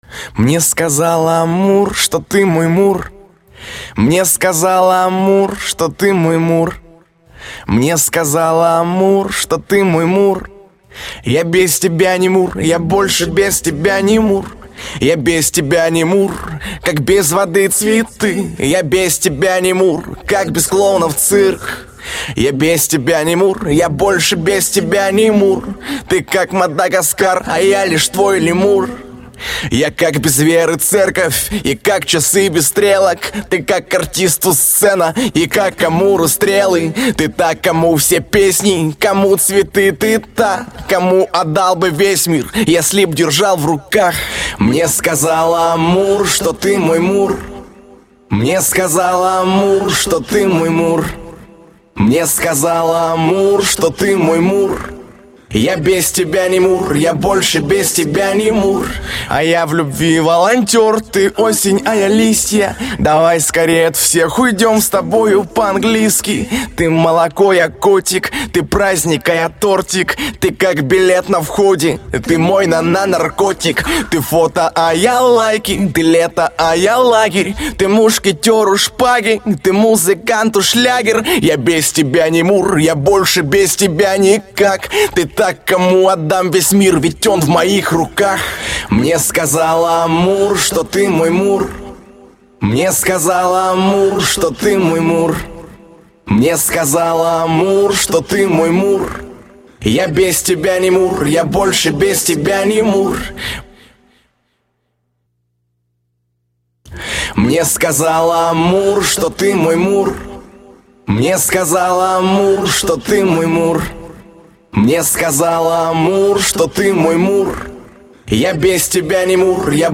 Главная » Файлы » Акапеллы » Скачать Русские акапеллы